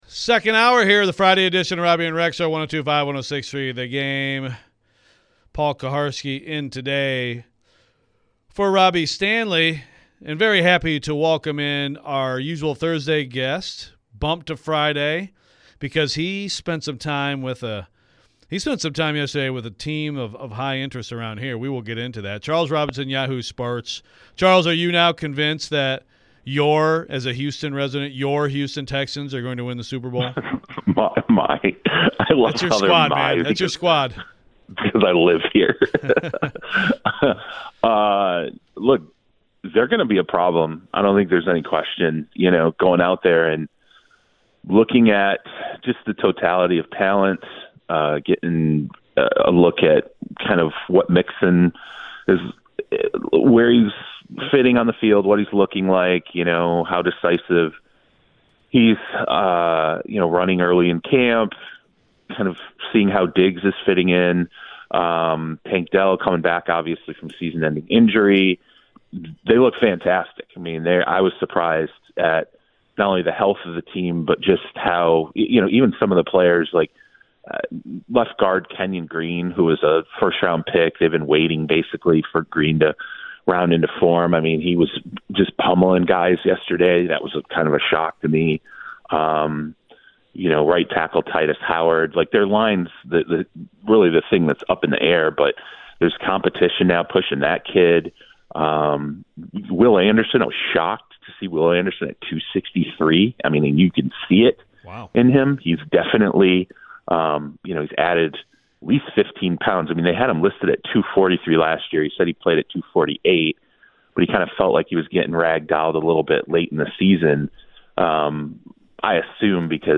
Charles Robinson Interview (7-19-24)